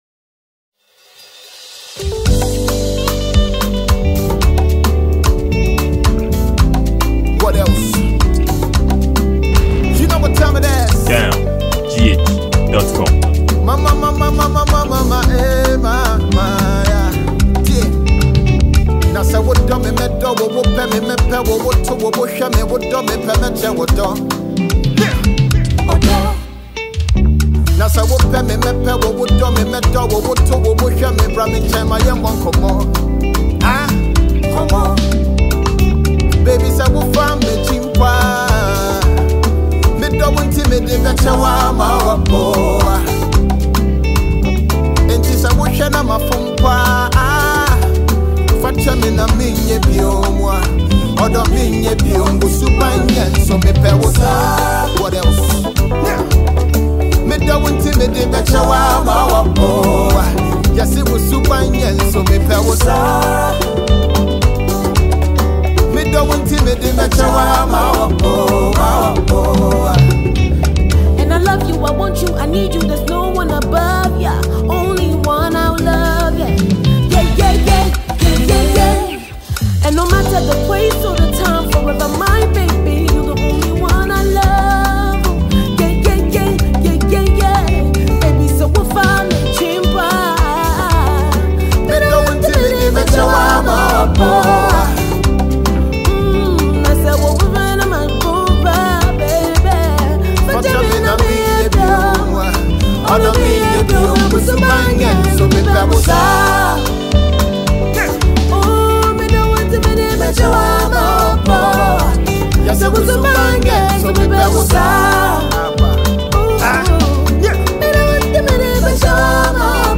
Ghana Music
Ghanaian rap legendary
featured Ghanaian renowned female singer